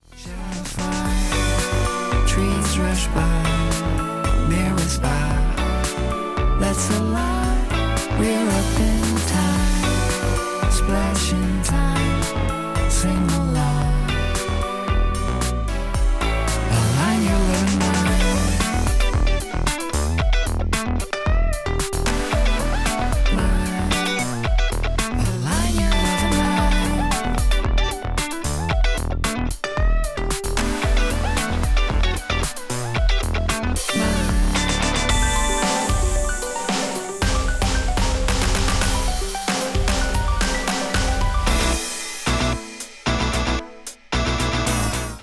Japanese New Wave / Rock Pop